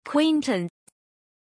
Pronunciation of Quinton
pronunciation-quinton-zh.mp3